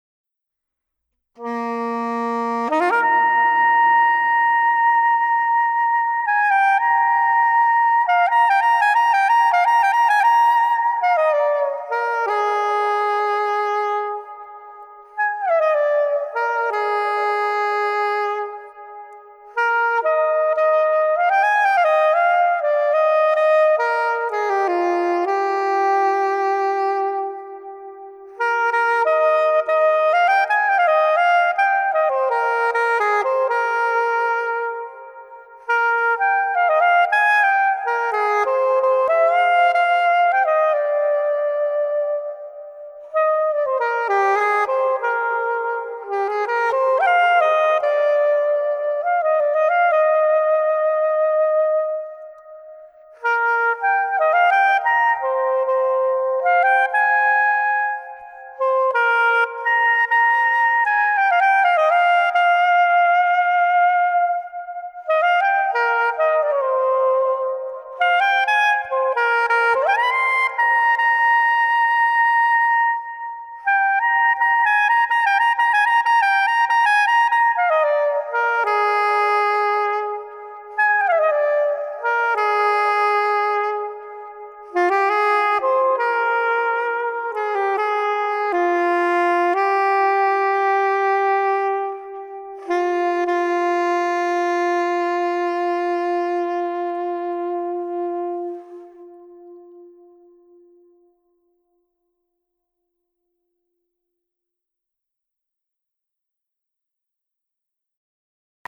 improvisation1
improvisation.mp3